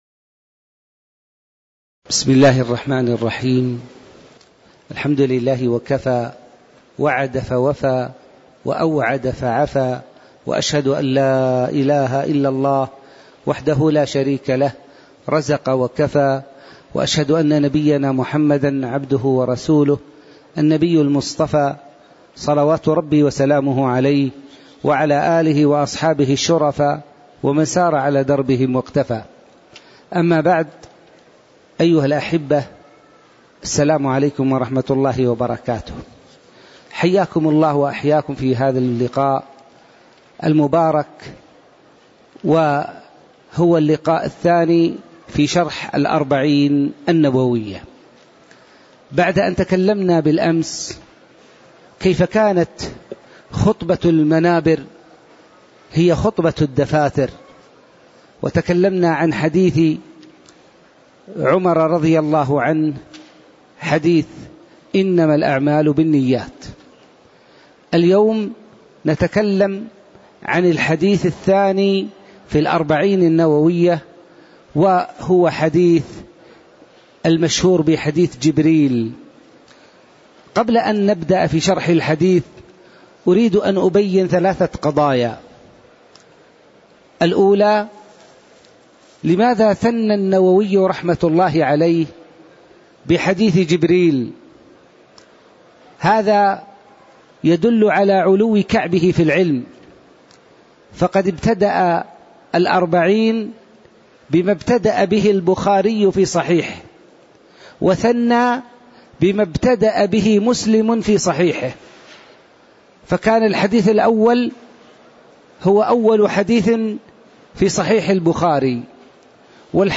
تاريخ النشر ٤ جمادى الآخرة ١٤٣٧ هـ المكان: المسجد النبوي الشيخ